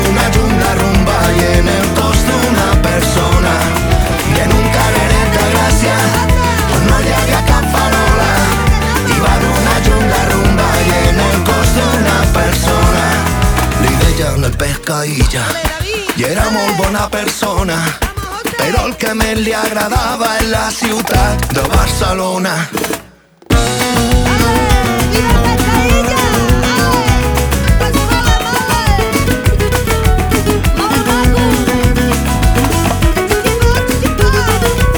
Скачать припев
Pop Latino